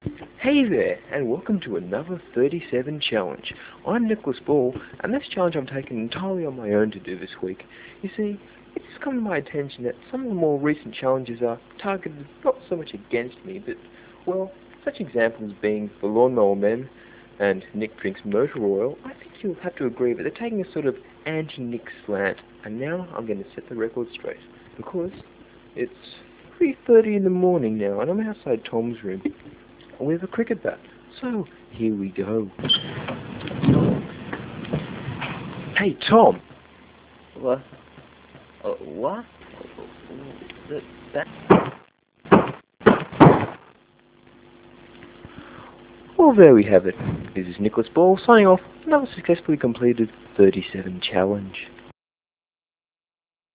The proprietors of 37zone regret any offence caused by this sketch.